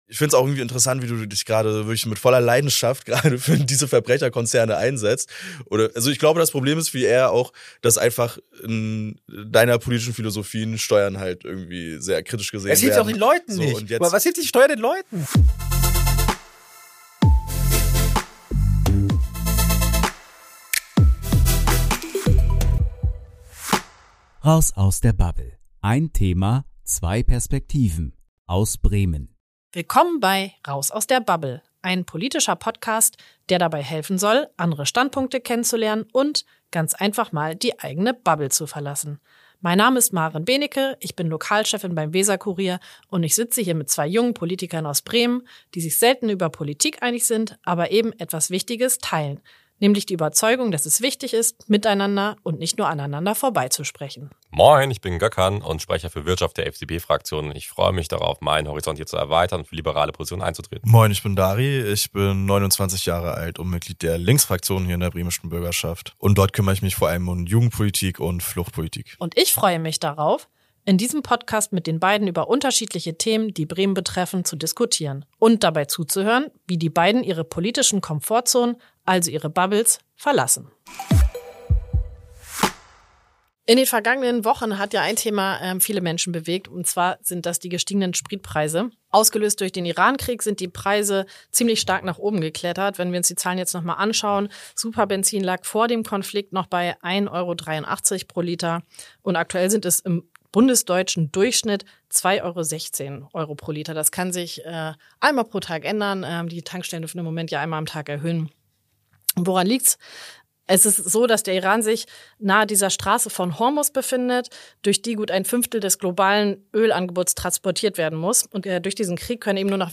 Ein Streitgespräch über Moral, Marktlogik – und die Grenzen staatlicher Eingriffe.